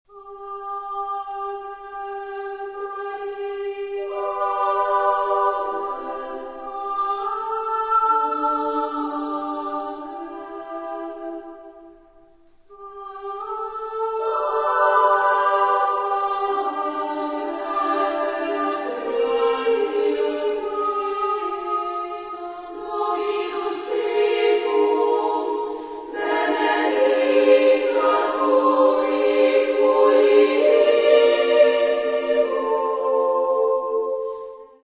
Genre-Style-Form: Choir ; Folk music
Mood of the piece: andante
Type of Choir: SSA (div)  (3 women voices )